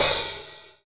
HAT KLB OH0E.wav